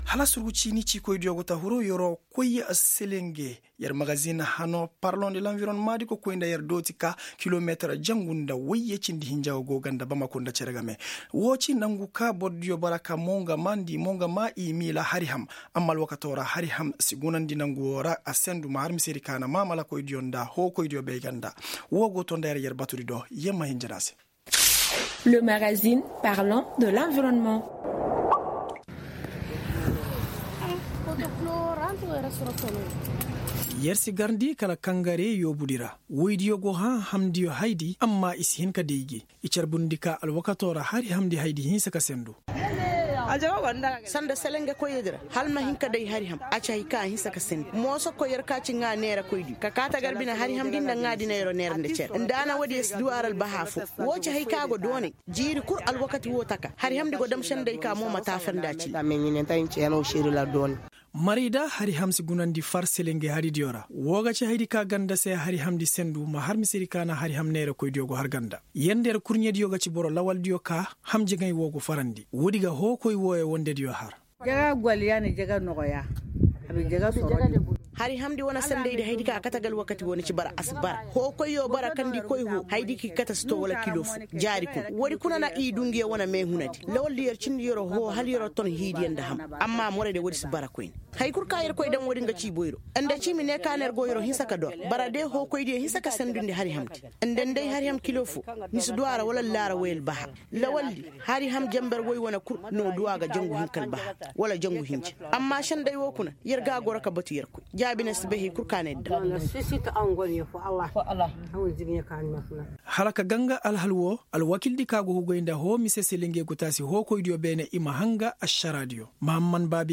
Magazine en sonrhai: Télécharger